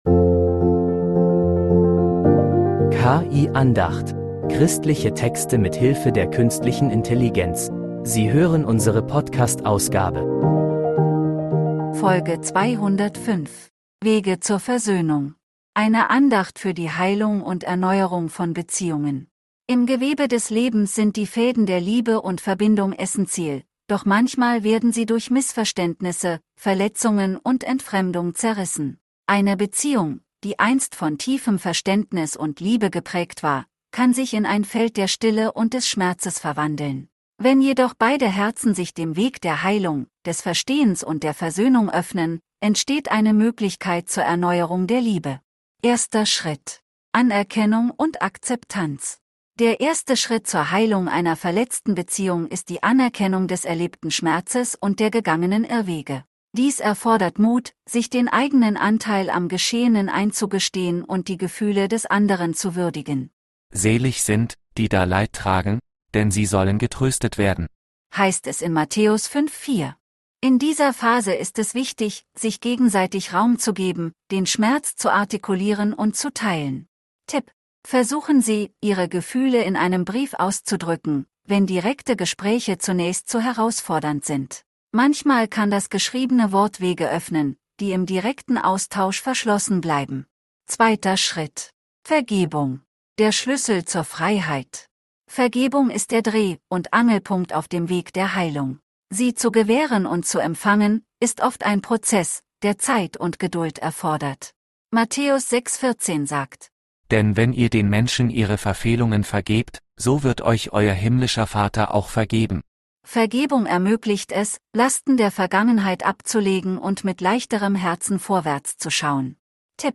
Eine Andacht für die Heilung und Erneuerung von Beziehungen